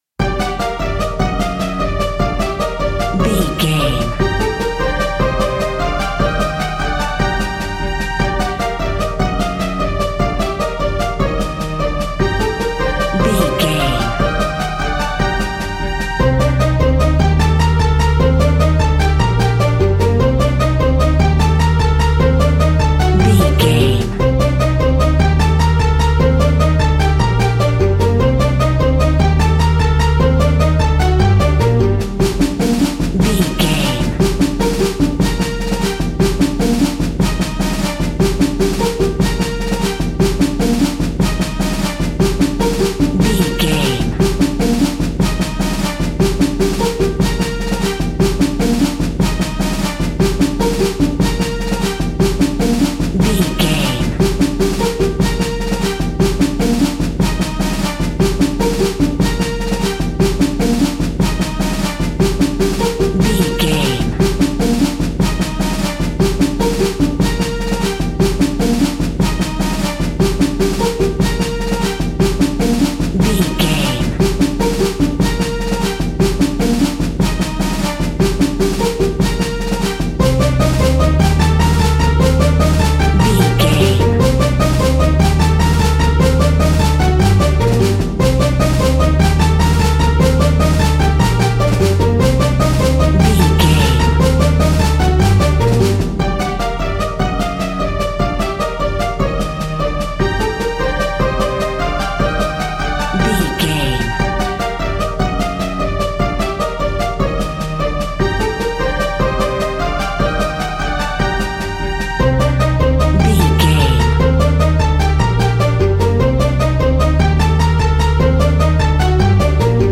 In-crescendo
Aeolian/Minor
Fast
tension
ominous
dark
eerie
energetic
strings
drums
bass guitar
horror music